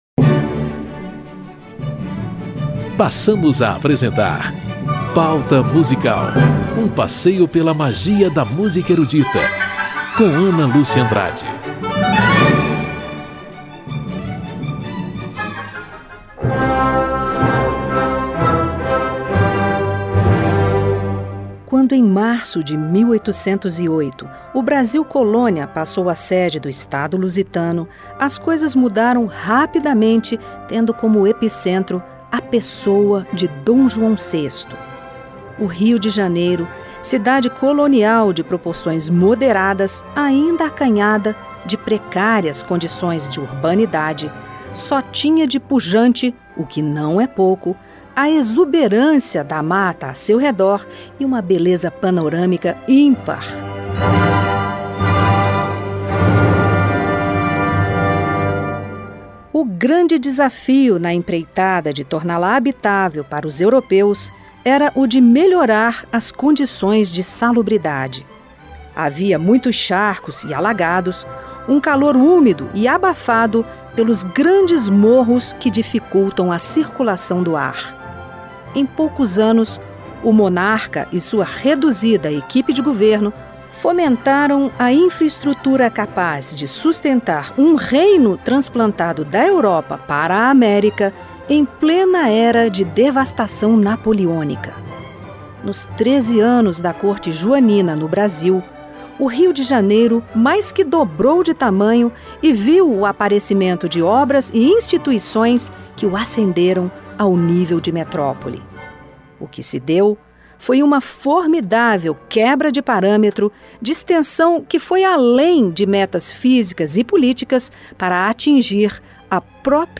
Na Corte de D. João VI, um austríaco em missão no Brasil cravou um marco na história de nossa música ao criar repertório de câmara, compor a primeira sinfonia escrita em solo brasileiro, misturar clássico e popular e inspirar linhas melódicas para o Hino Nacional Brasileiro. Cantores e instrumentistas brasileiros interpretam modinhas cariocas e obras de Sigismund Neukomm.